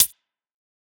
Index of /musicradar/ultimate-hihat-samples/Hits/ElectroHat B
UHH_ElectroHatB_Hit-02.wav